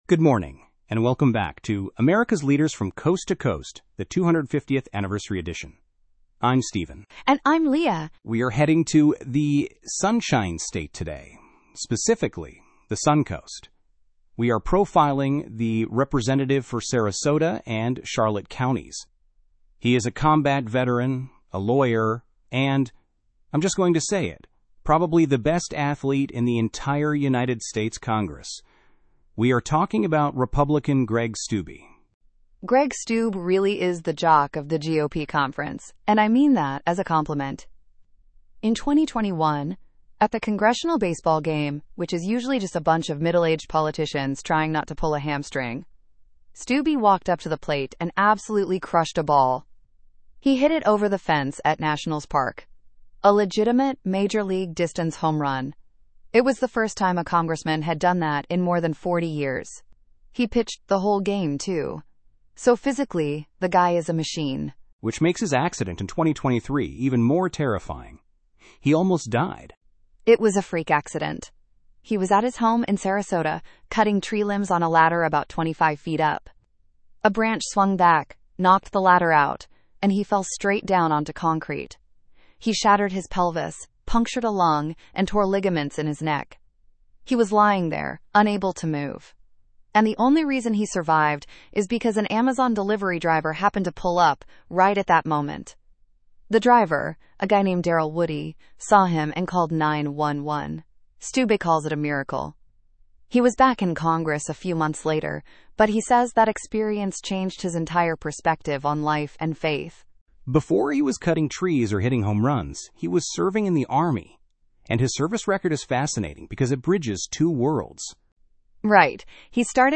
Greg Steube (pronounced "Stew-bee") is a combat veteran and arguably the best athlete in Congress.